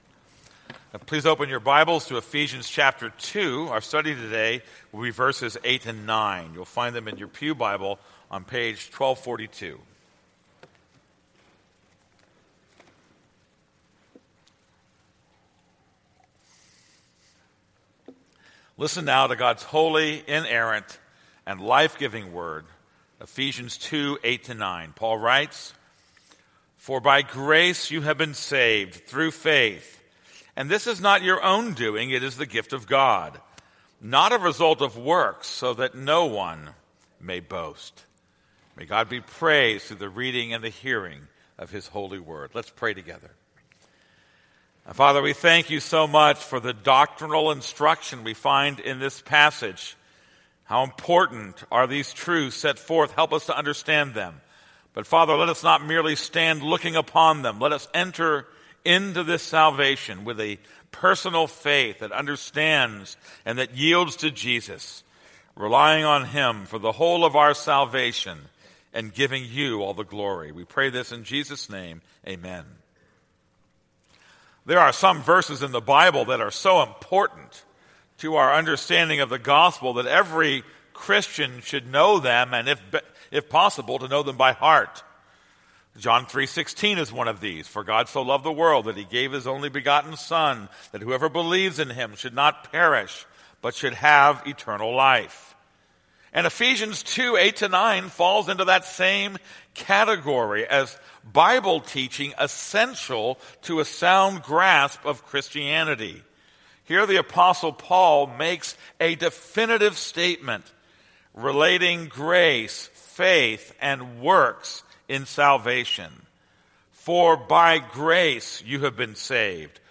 This is a sermon on Ephesians 2:8-9.